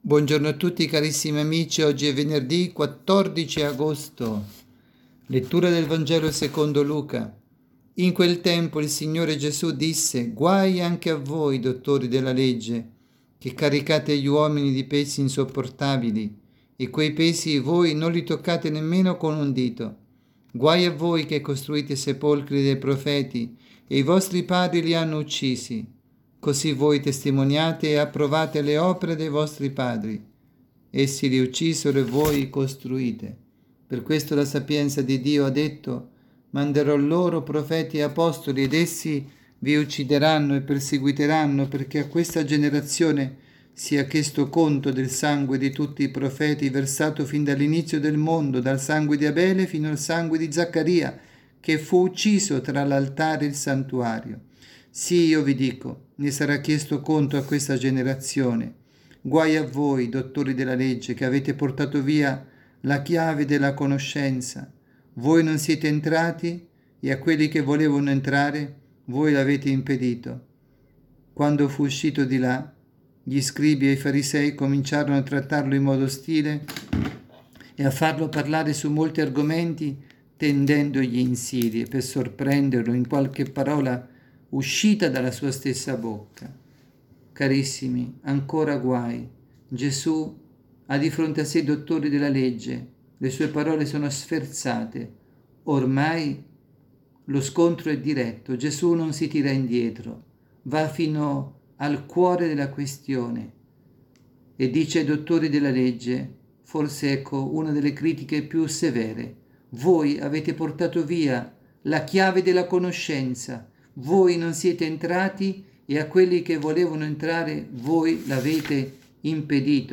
avvisi, Omelie
dalla Parrocchia S. Rita – Milano